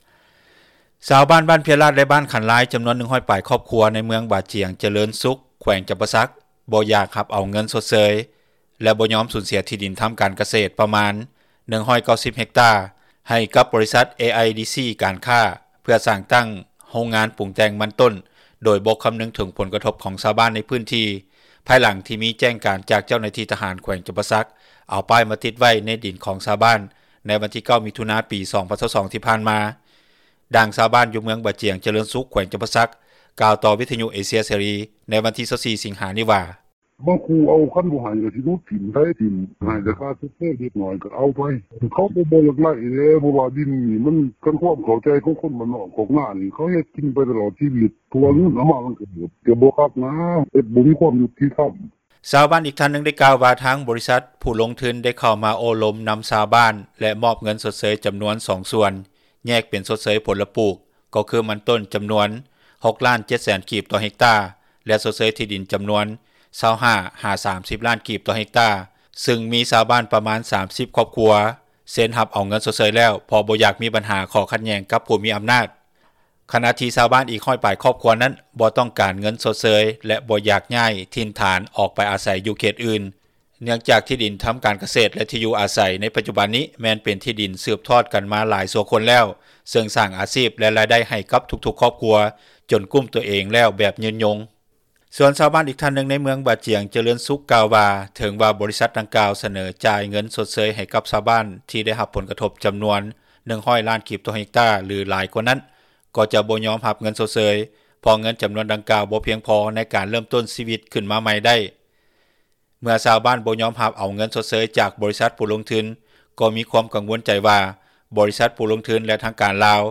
ດັ່ງຊາວບ້ານ ຢູ່ເມືອງບາຈຽງຈະເຣີນສຸກ ແຂວງຈຳປາສັກ ກ່າວຕໍ່ວິທຍຸເອເຊັຽເສຣີ ໃນວັນທີ 24 ສິງຫານີ້ວ່າ:
ດັ່ງຊາວບ້ານ ຜູ້ນີ້ກ່າວຕໍ່ວິທຍຸເອເຊັຽເສຣີ ໃນມື້ດຽວກັນນີ້ວ່າ: